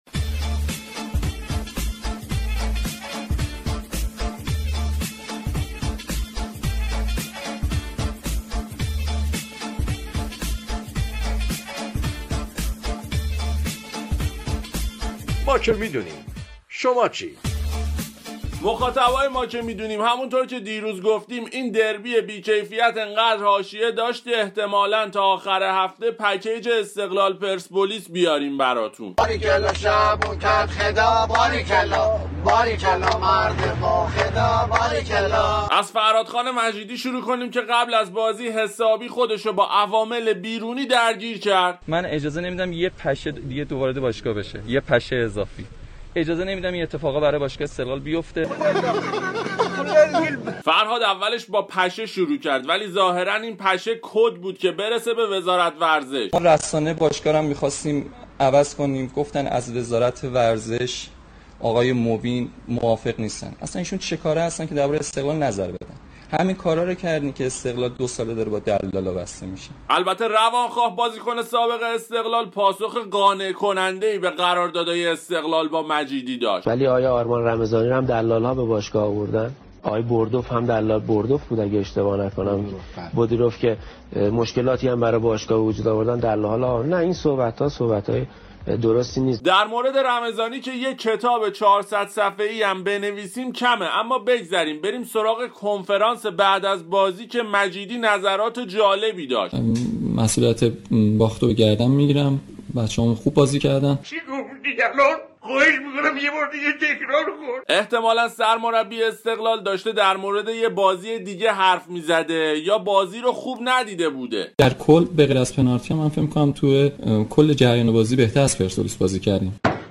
شوخی رادیو با حاشیه های دربی 95